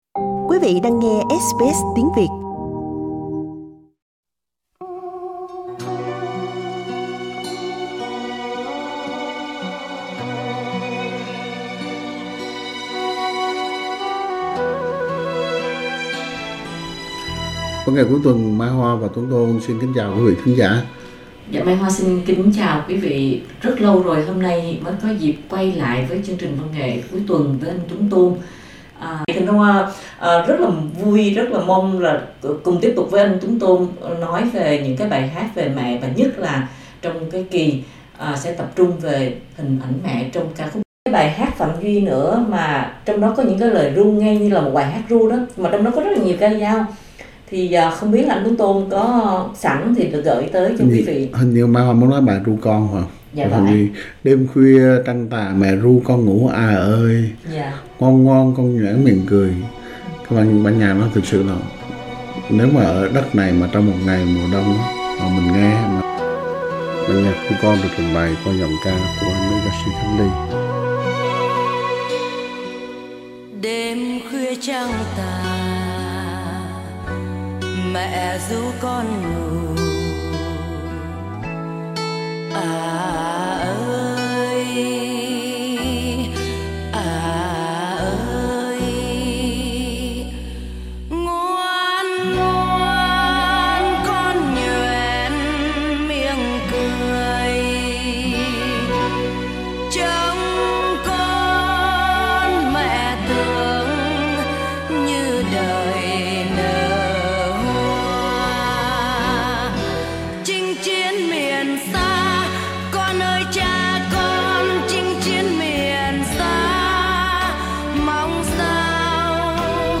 Những bản nhạc viết về Mẹ hầu hết đều êm ái, du dương và thấm đẫm tình yêu thương của nhạc sĩ (dù chuyên nghiệp hay tài tử) dành cho Mẹ.